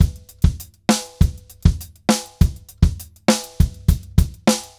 Drums_Baion 100_2.wav